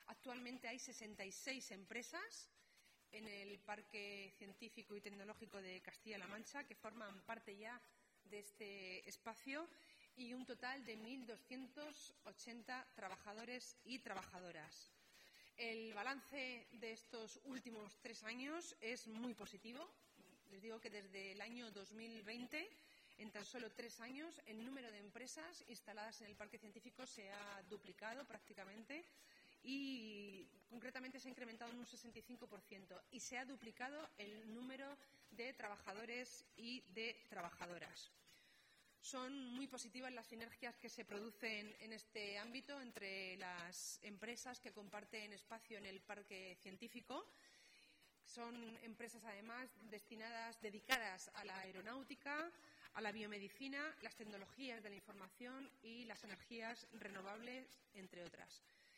Con esta actuación, tal y como ha explicado hoy, en rueda de prensa, la consejera Portavoz, Esther Padilla, lo que se pretende es impulsar la bio-incubadora de empresas del Parque Científico y Tecnológico de Castilla-La Mancha (PCTCLM), debido a la necesidad de mejorar las instalaciones existentes para la consolidación y mejora de sus infraestructuras científico-tecnológicas.